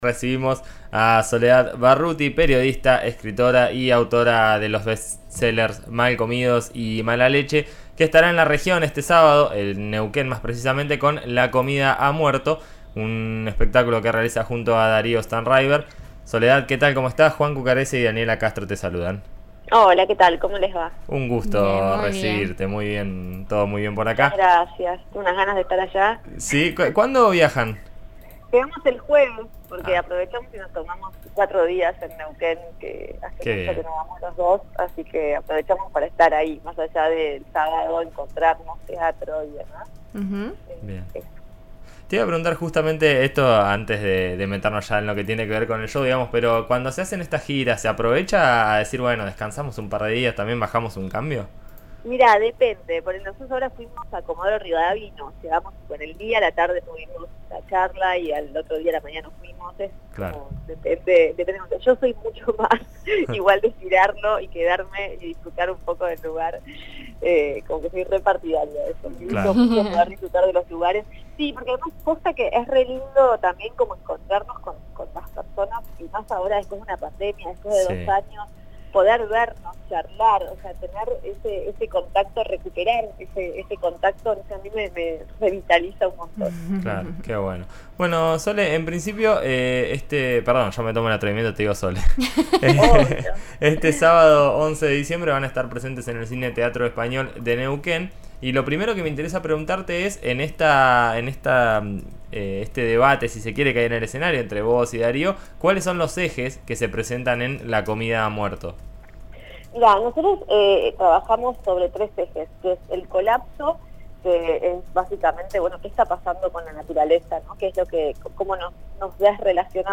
Al aire de En Eso Estamos, por RN RADIO (FM 89.3), reflexionó sobre cómo entendemos los procesos alimentarios.
Al aire de En Eso Estamos, por RN RADIO (FM 89.3), Barruti dejó varias frases interesantes para repasar.